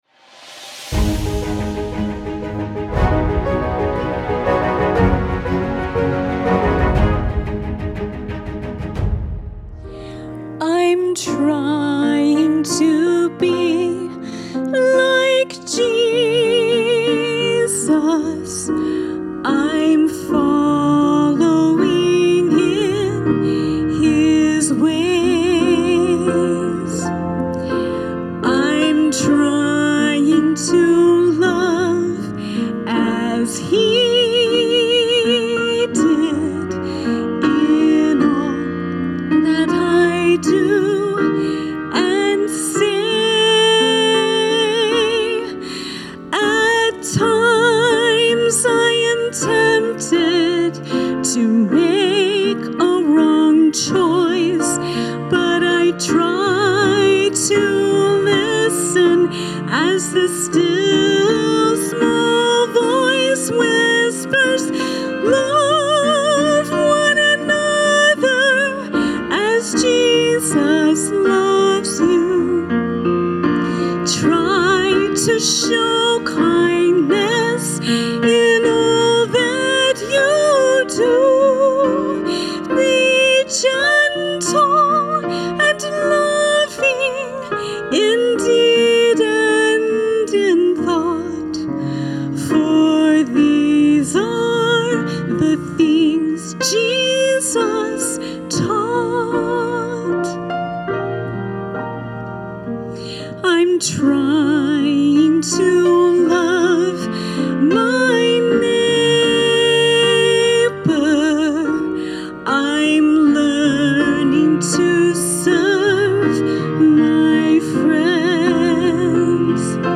Central Sermons